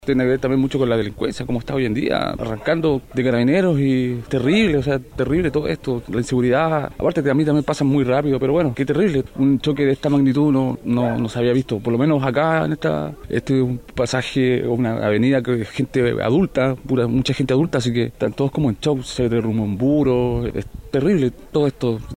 cu-accidente-belloto-sur-vecino.mp3